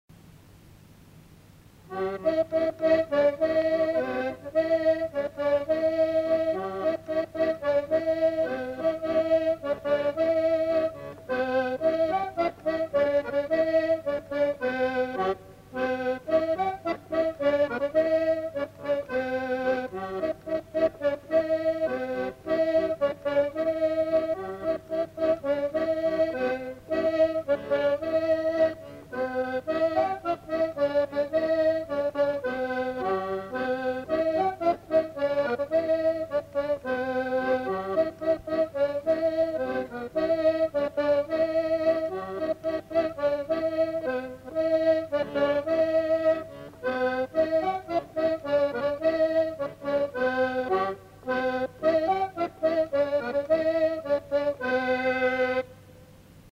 Lieu : Monclar d'Agenais
Genre : morceau instrumental
Instrument de musique : accordéon diatonique